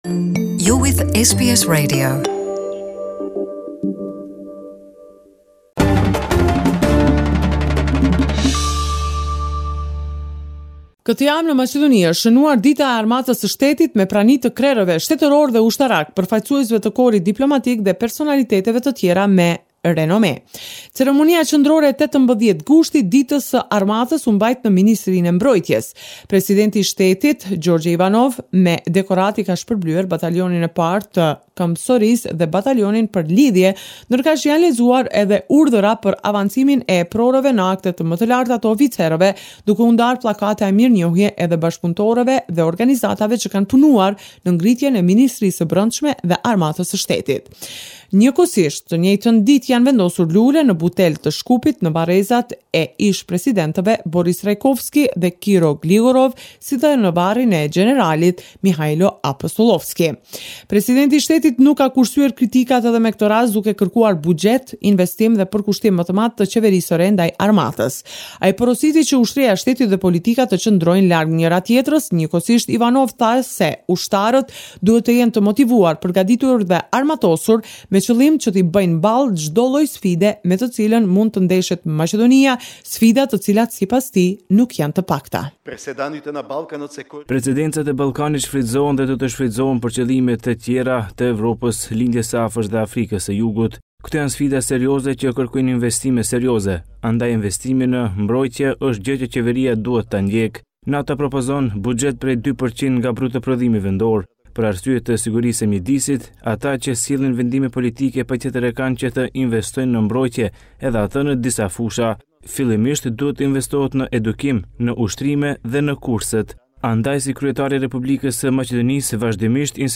This is a report summarising the latest developments in news and current affairs in Macedonia